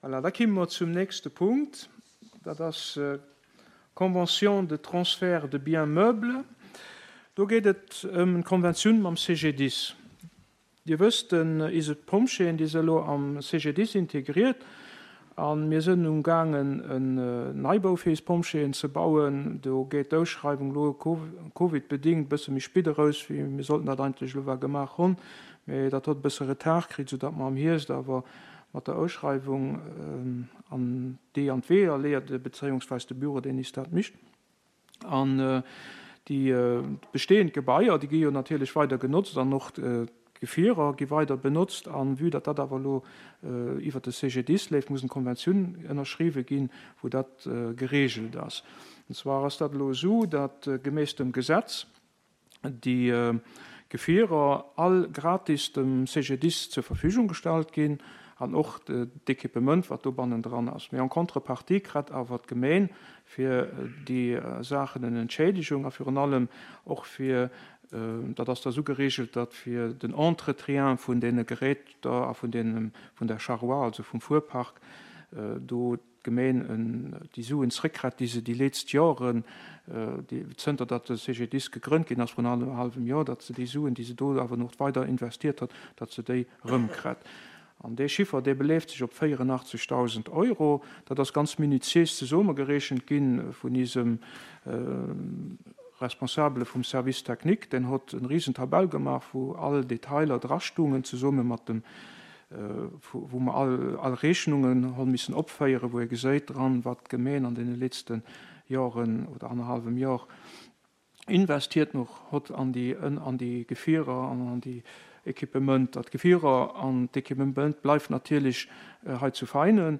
le jeudi, 30 juillet 2020 à 14:00 heures au Centre Culturel Larei salle Bessling